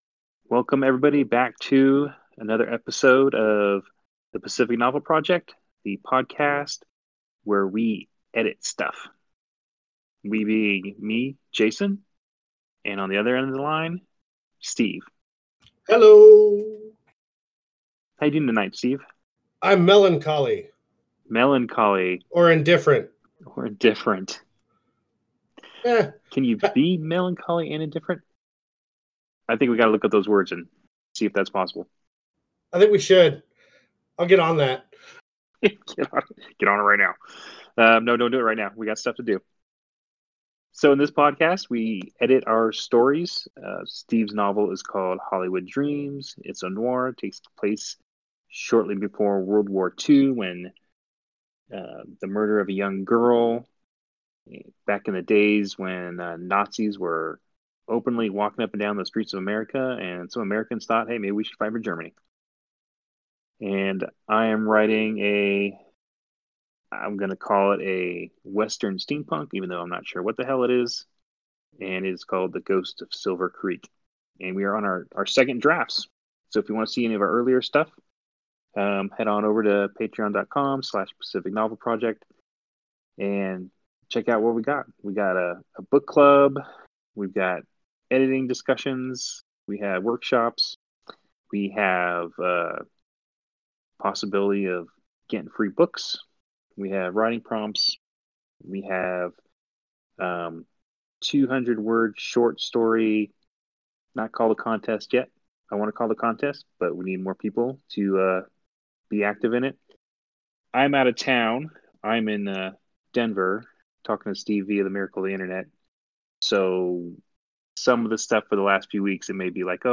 there is some heavy handed editing and we forwent a lot in-depth discussion.